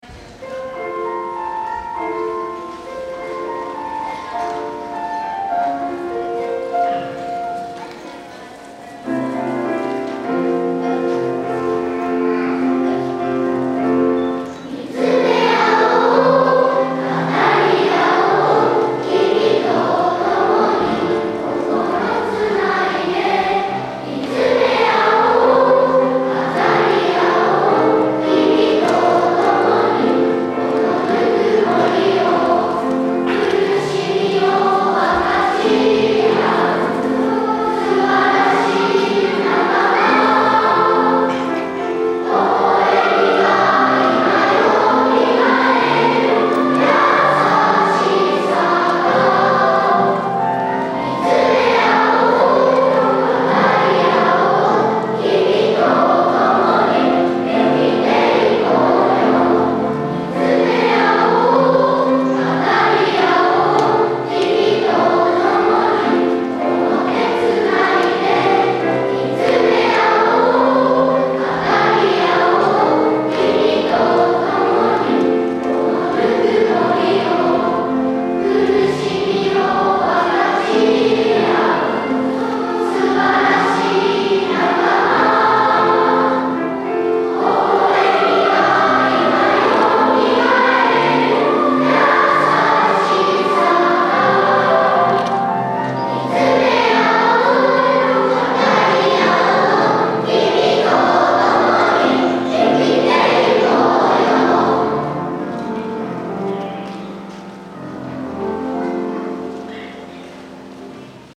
曲は会場２部合唱「
子どもたちの歌声とみなさんの歌声がハーモニーとなり会場に響き渡りました。